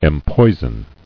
[em·poi·son]